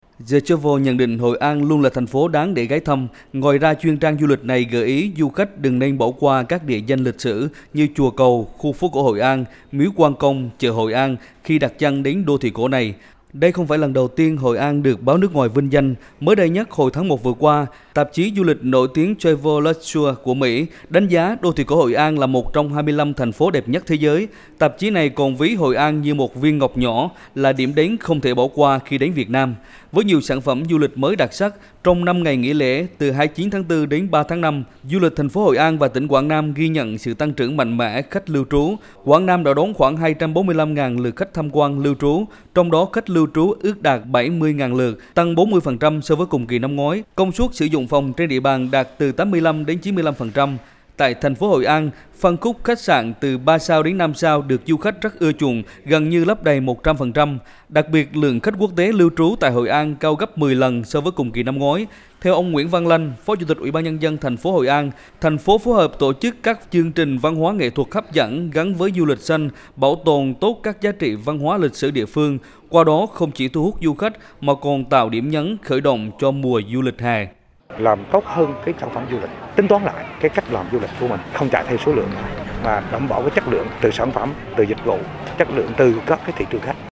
THỜI SỰ Tin thời sự